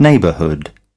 24. neighborhood (n) /ˈneɪbərhʊd/: khu phố, vùng lân cận